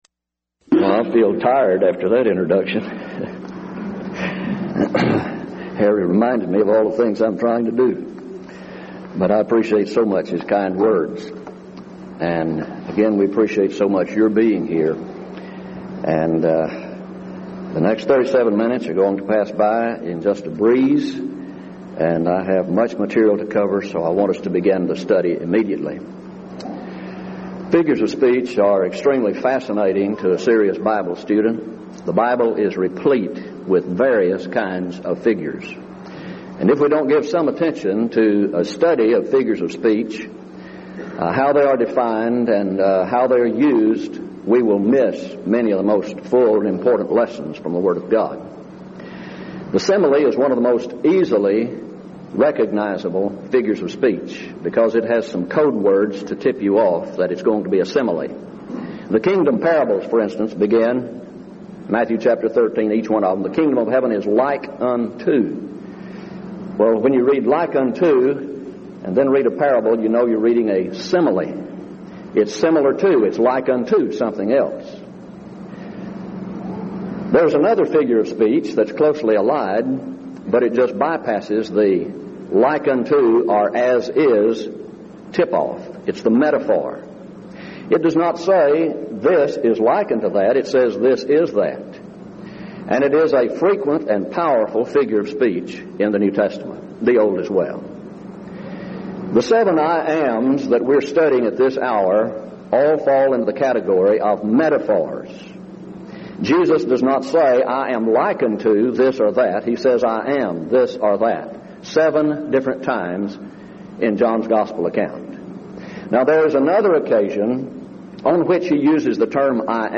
Series: Denton Lectures Event: 1999 Denton Lectures